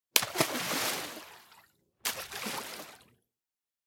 sounds / liquid / splash2.ogg
splash2.ogg